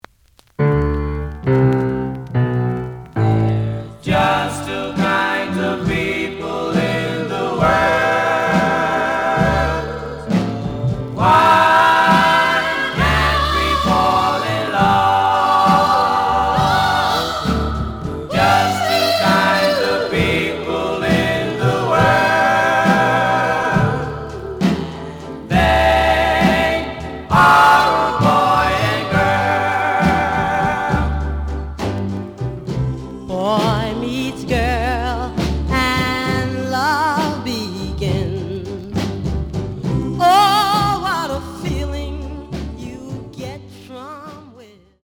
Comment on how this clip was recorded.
The audio sample is recorded from the actual item. ●Format: 7 inch Slight edge warp. But doesn't affect playing. Plays good.)